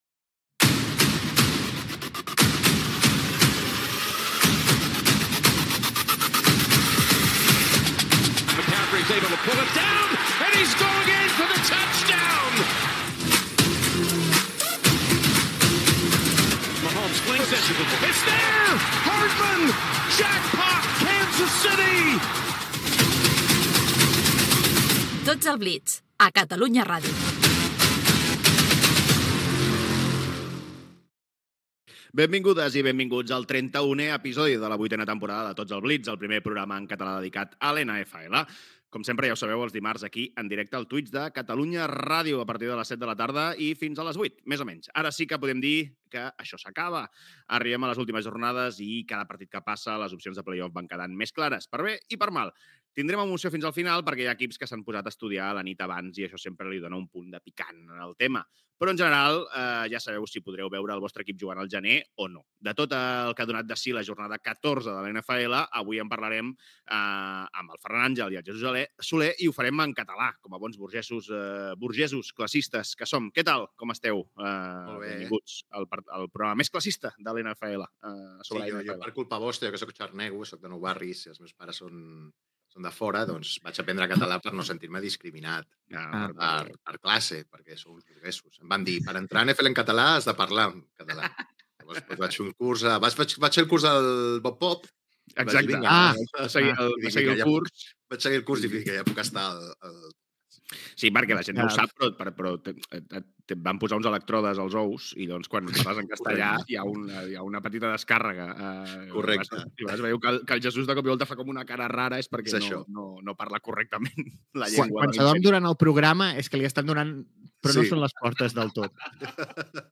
Descripció Careta, presentació del programa 31 dedicat a la Lliga de Futbol Nacional (National Football League) a la xarxa Twitch. Diàleg sobre el català.
Extret del canal de Twitch de Catalunya Ràdio